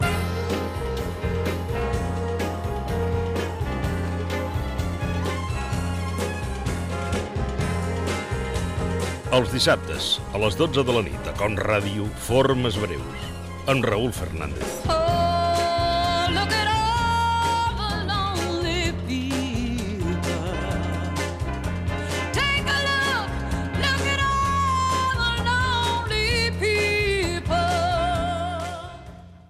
Promoció del programa
FM